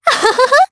Leo-Vox_Happy3_jp.wav